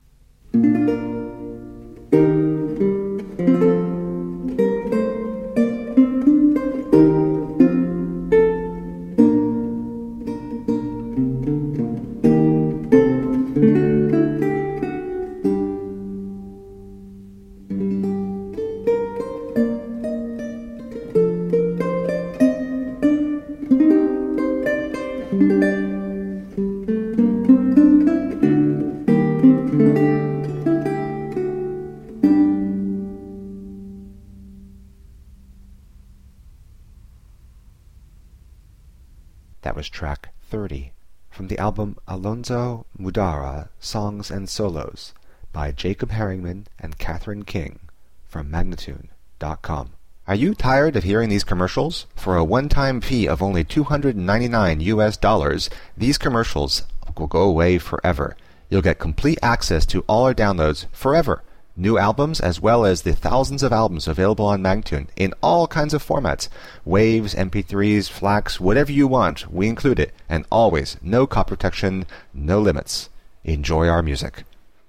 Renaissance songs.